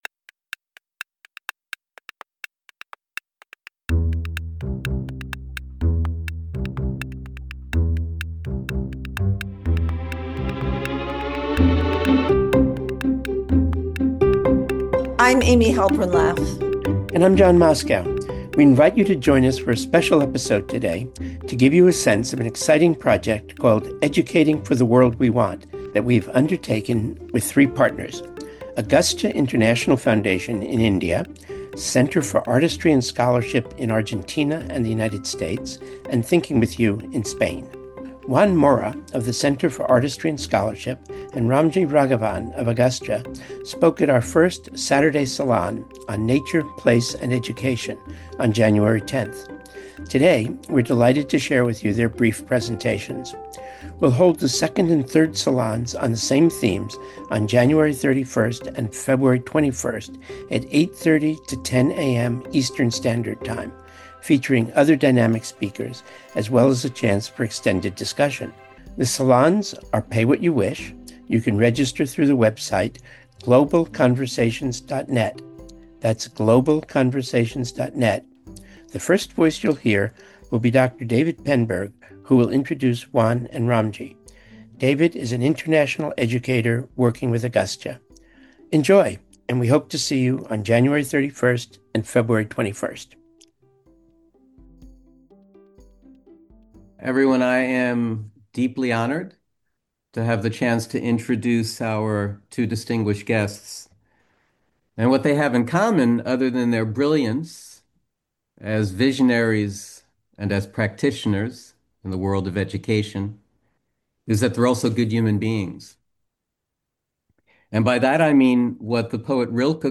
We share brief presentations from the third of the “Saturday Salons” that Ethical Schools is sponsoring with three international partners.